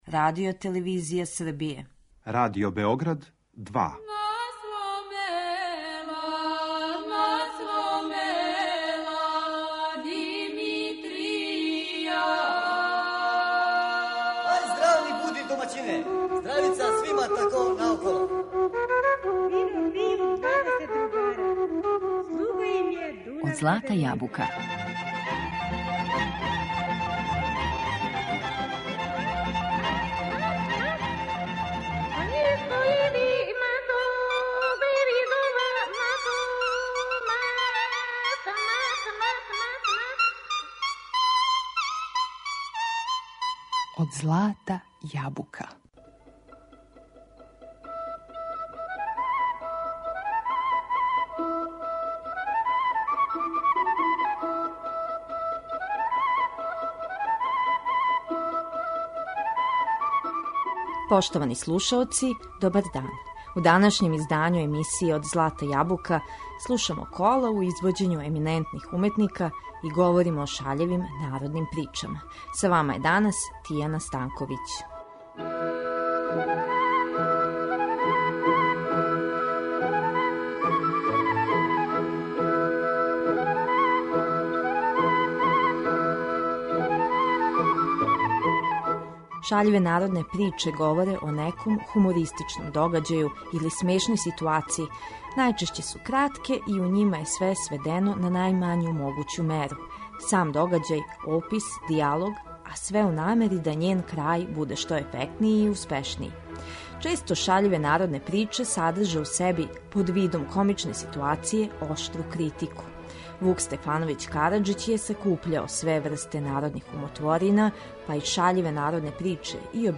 У данашњем издању емисије Од злата јабука говорићемо о честим јунацима ових кратких прича и слушати најлепша остварења народне музике.